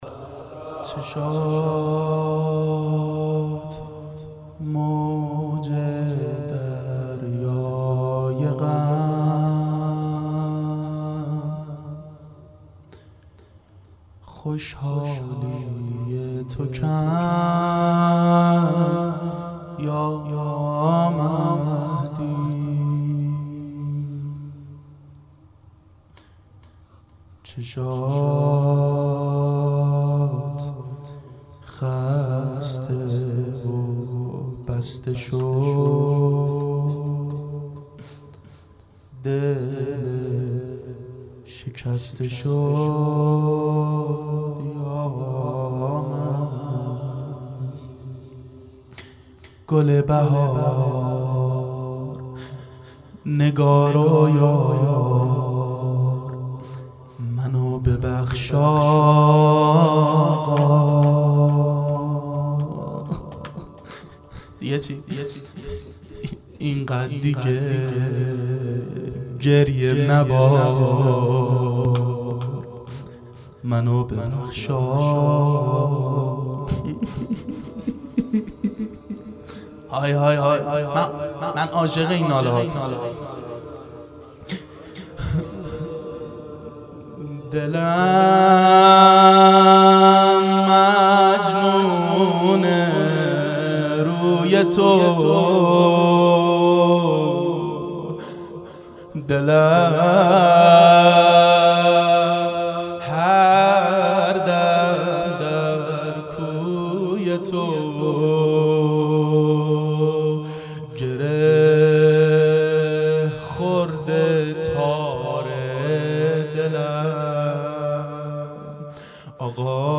آقا شرمنده شمام مناجات با امام زمان علیه السلام متاسفانه مرورگر شما، قابیلت پخش فایل های صوتی تصویری را در قالب HTML5 دارا نمی باشد.
دهه اول صفر سال 1390 هیئت شیفتگان حضرت رقیه س شب اول